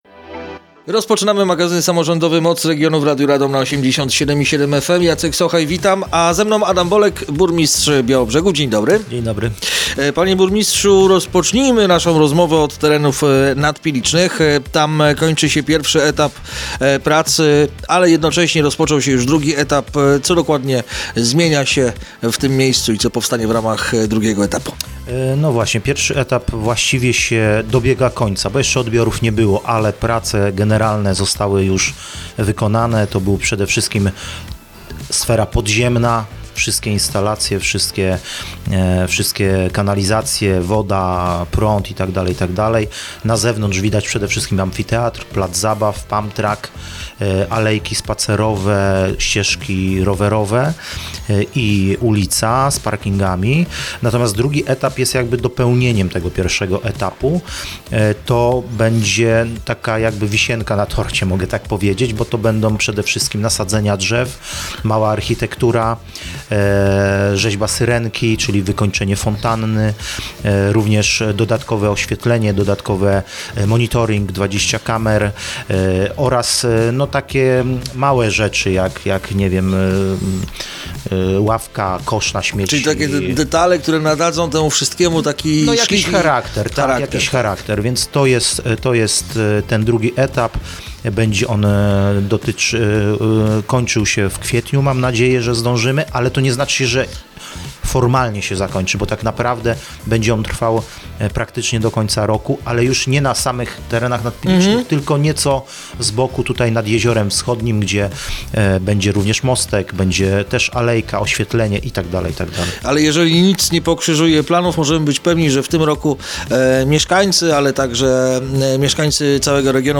Rozmowa dostępna jest również na facebookowym profilu Radia Radom: